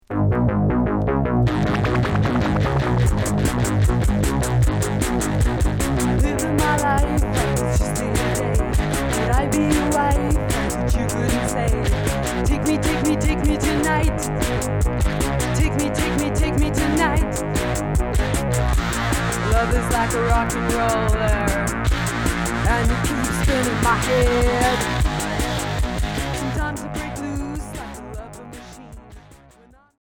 Cold wave Punk